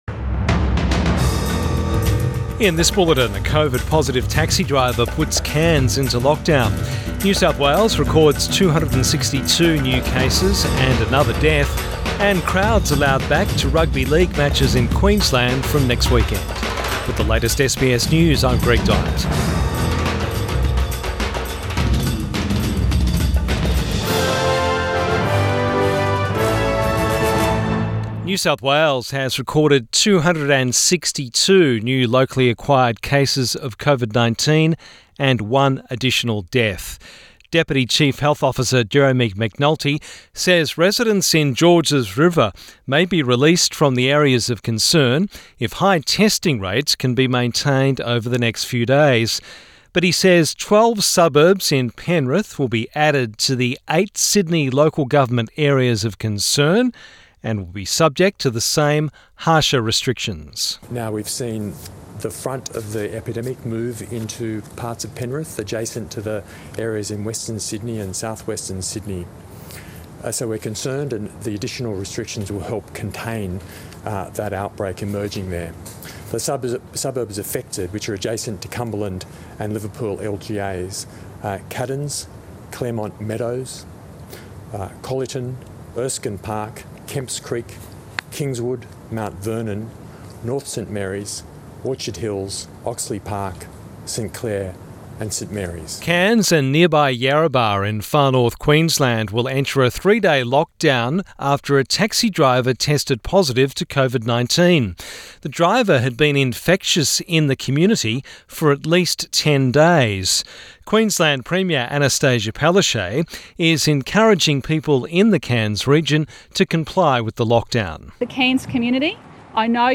PM bulletin 8 August 2021